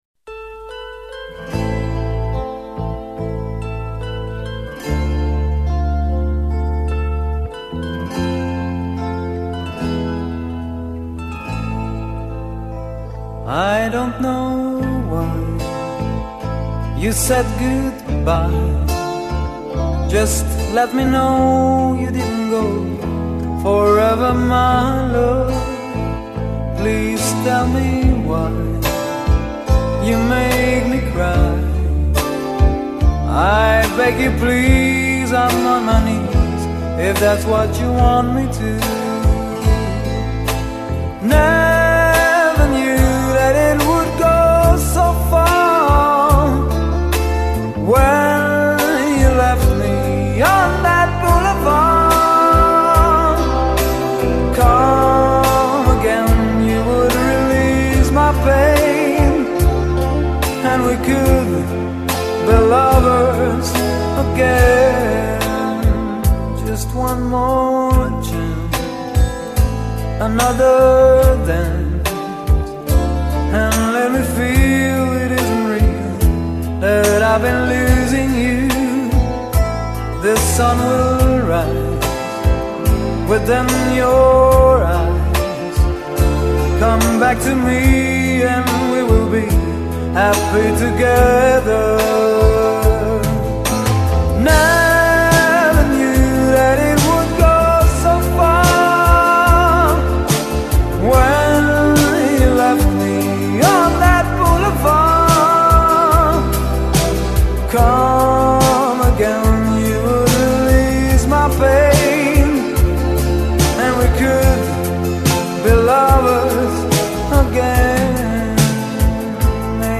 Битрейт пониже, но как-то лучше звучит